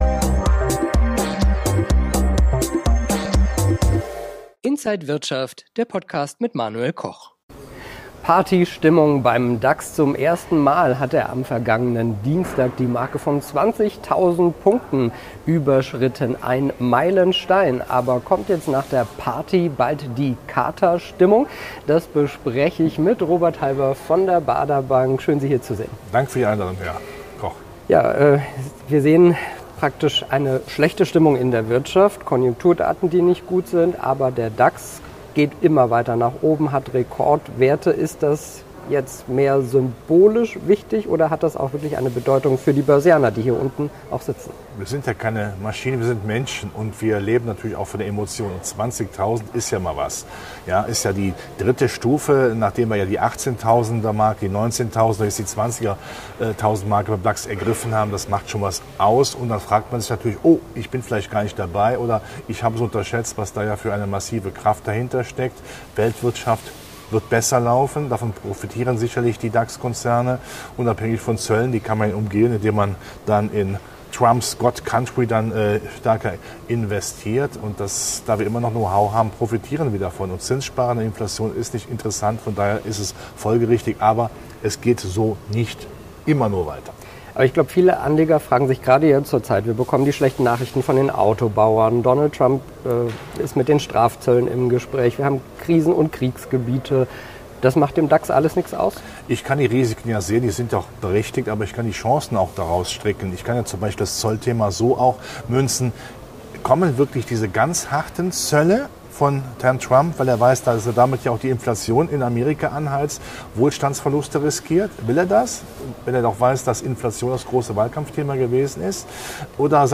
Alle Details im Interview von Inside
an der Frankfurter Börse